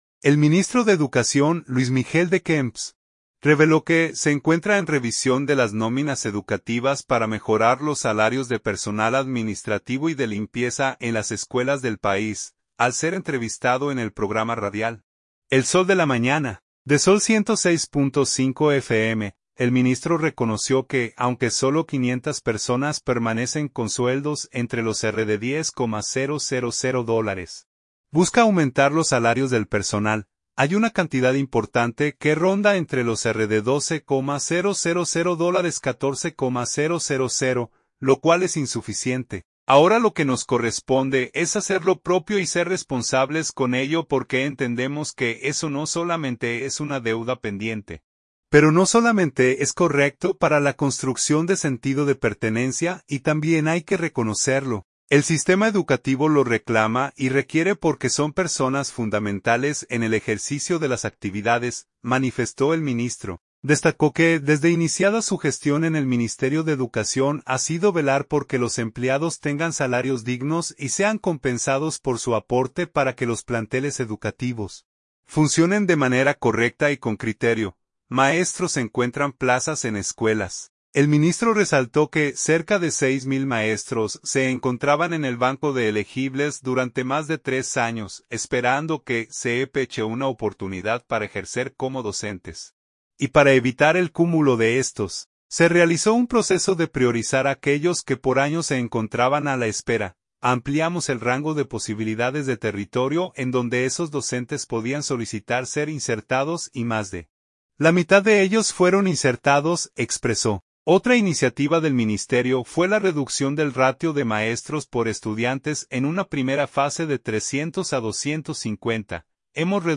Al ser entrevistado en el programa radial El Sol de la Mañana, de Zol 106.5 FM, el ministro reconoció que, aunque solo 500 personas permanecen con sueldos entre los RD$10,000, busca aumentar los salarios del personal.